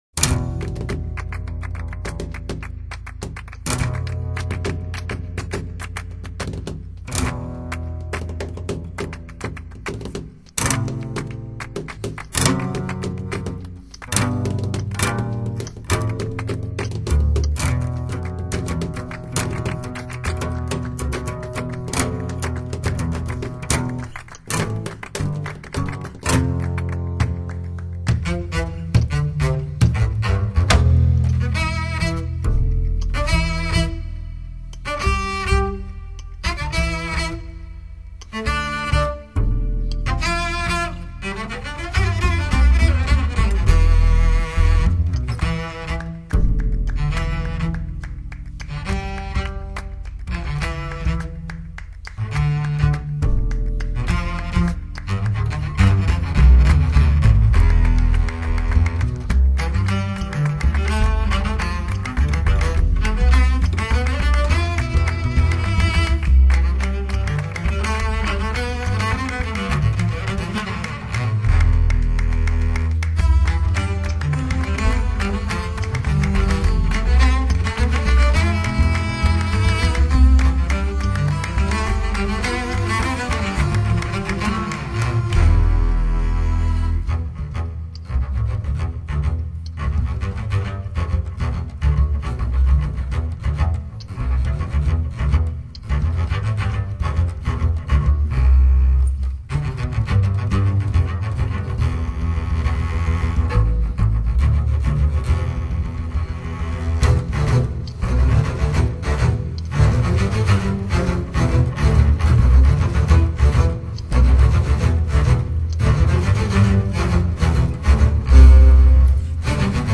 本碟谈不上劲爆震撼，却全面衡量低频质量，我喜欢第3，4段的定位，瞬态，空气感，更重要的低音细节，让人爱不惜手。